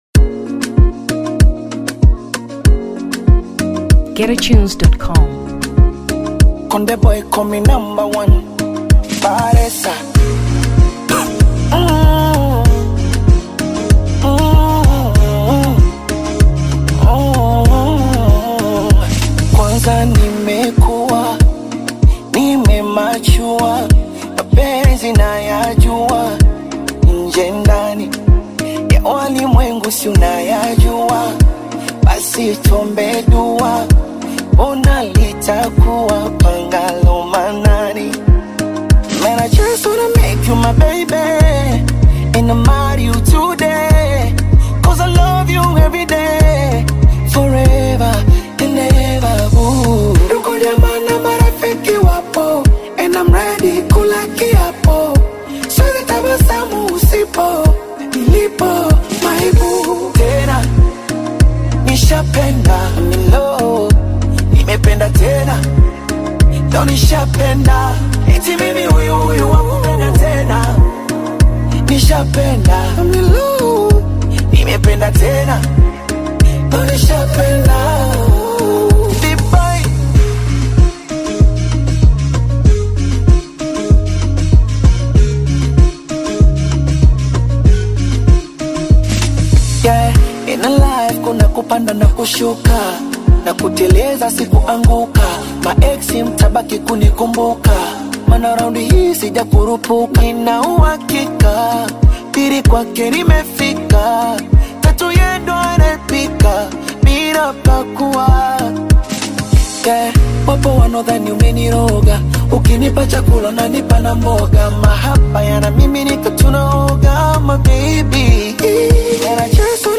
Electronic 2023 Tanzania